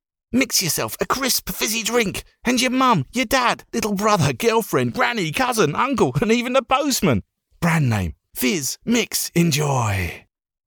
British English VO from London but based in glorious Yorkshire
Web Ad - Fizzy Drinks Brand - Young Adult Male